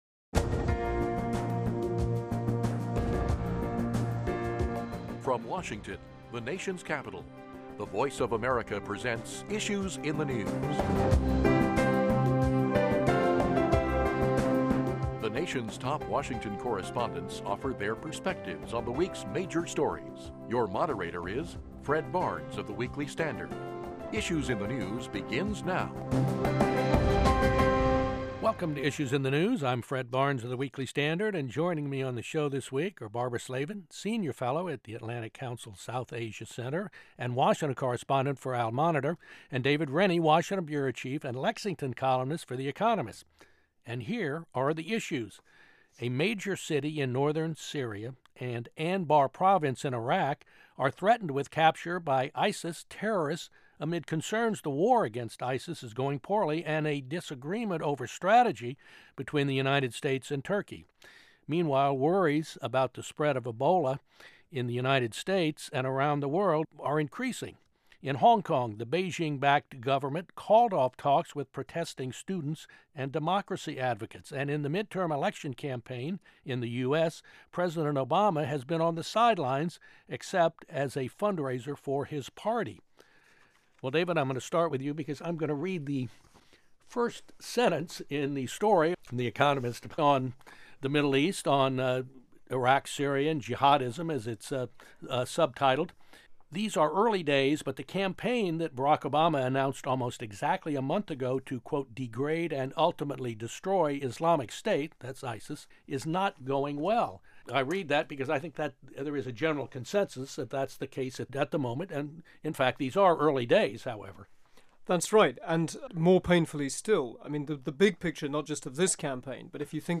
Moderator Fred Barnes, Executive Editor of The Weekly Standard